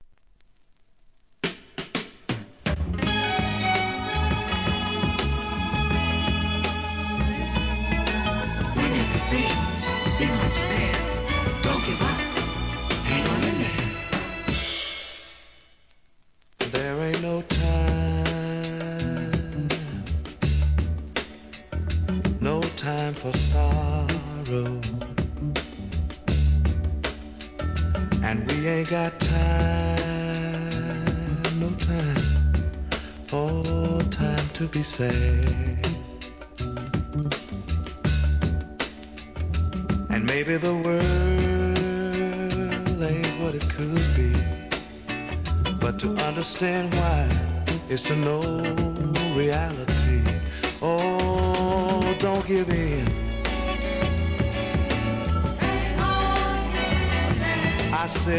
¥ 1,540 税込 関連カテゴリ SOUL/FUNK/etc...